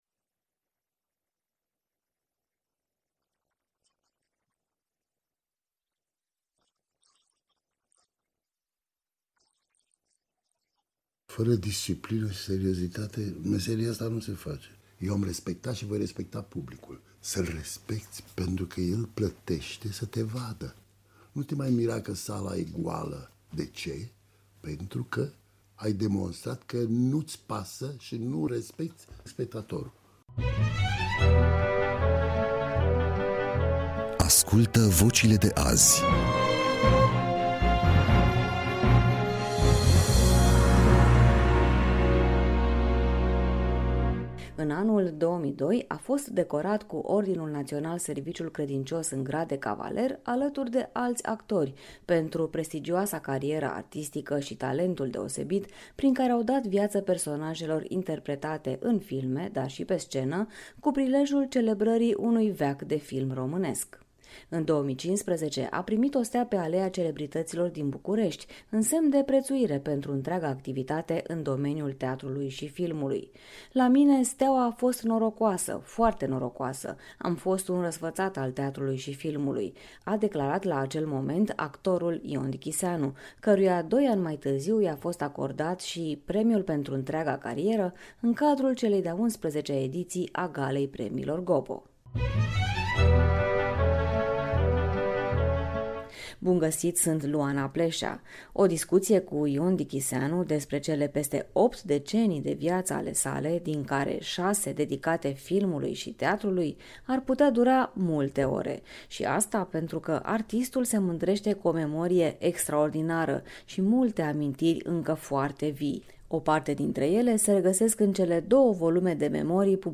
Interviu cu actorul Ion Dichiseanu in emisiunea Ascultă vocile de azi 30 dec 2019